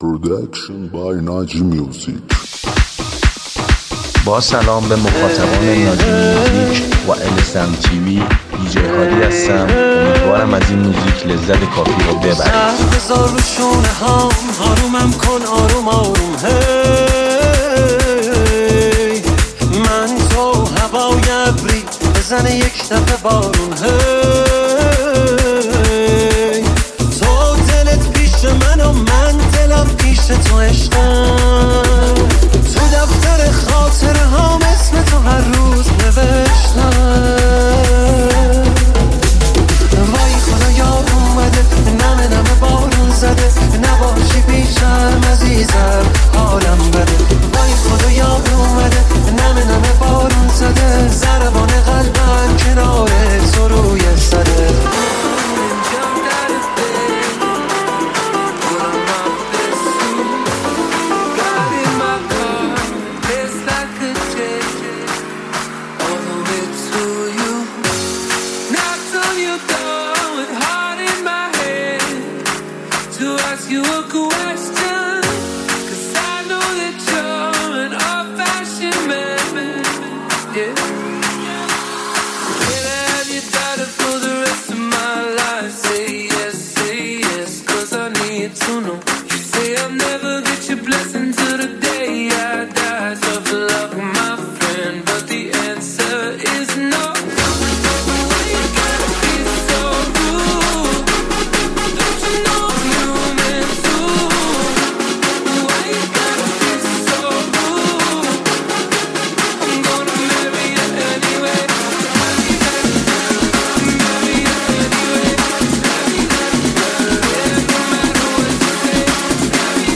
موزیک شاد مخصوص عید